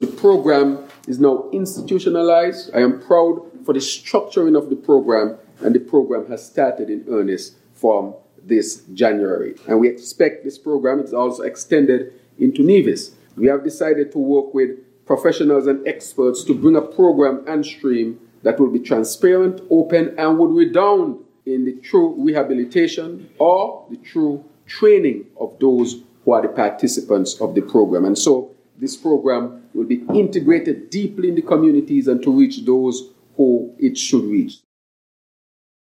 Prime Minister and Minister of National Security, Dr. Terrance Drew gave an update on the “ELEVATE” programme, which was dubbed the Alternative Lifestyle Pathways Programme, commonly referred to as the Peace Programme.